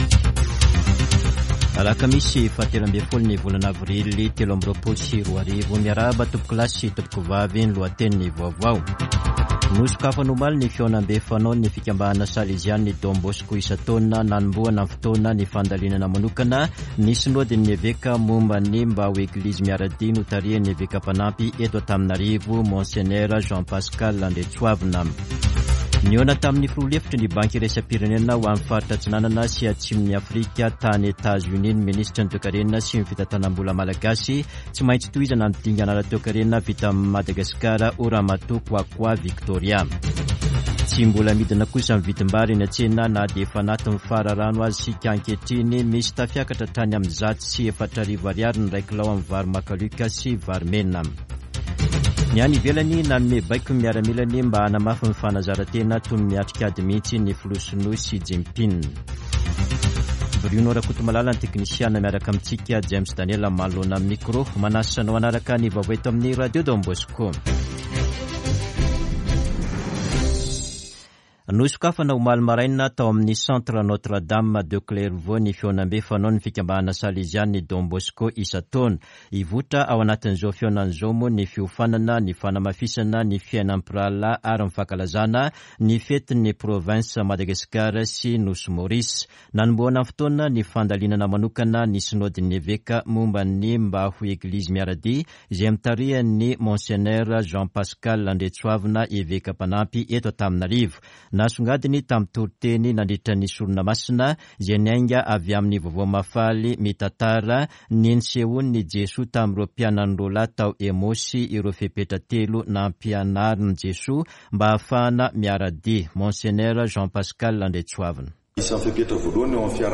[Vaovao maraina] Alakamisy 13 avrily 2023